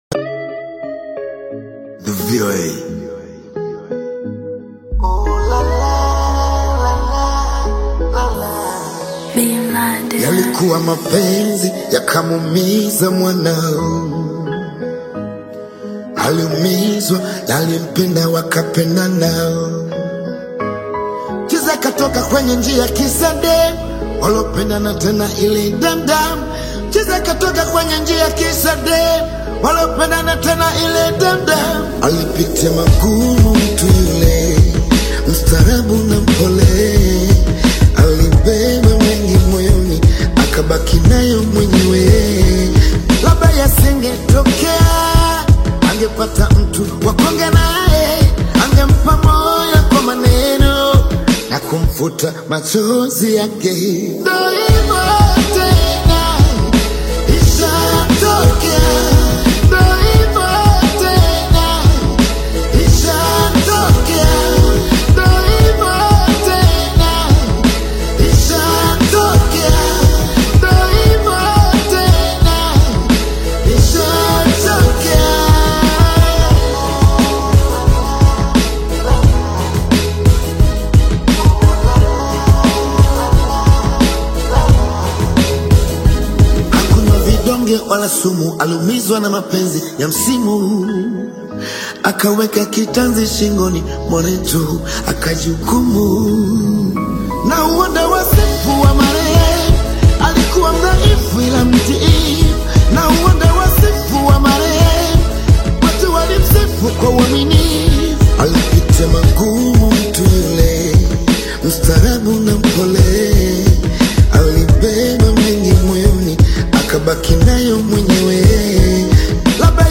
veteran vocals
BongoFlevaNarrative